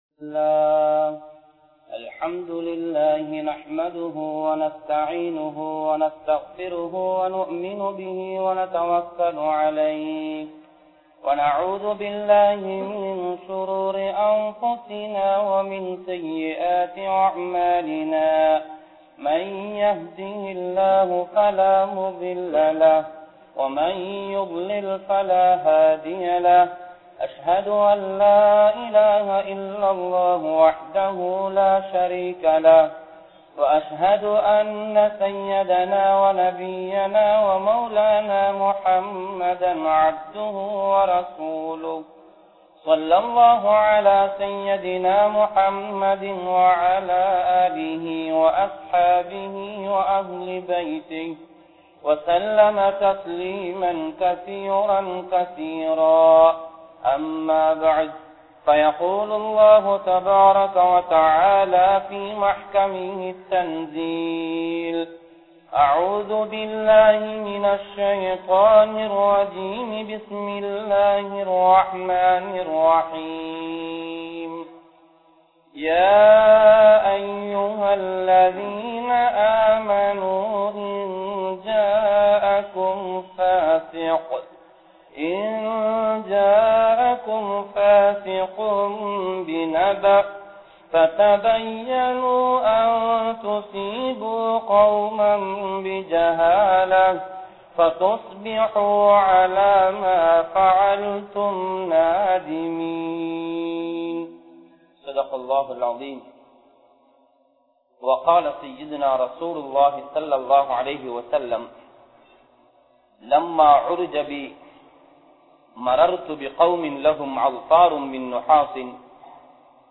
Kastangal Vara Kaaranam Enna? (கஷ்டங்கள் வரக் காரணம் என்ன?) | Audio Bayans | All Ceylon Muslim Youth Community | Addalaichenai
Negombo, Grand Jumua Masjith